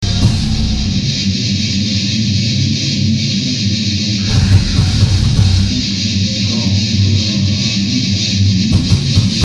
Heavy_Riff1_clip.mp3